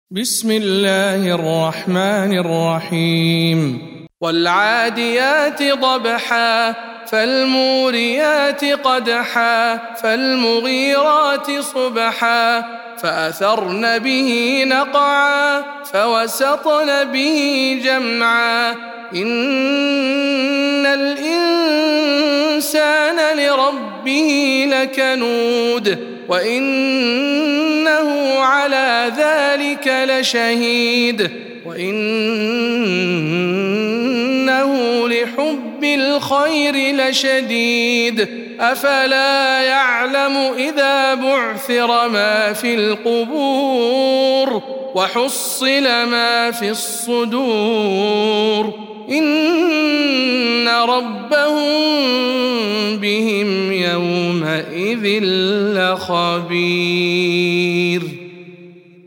سورة العاديات - رواية الدوري عن الكسائي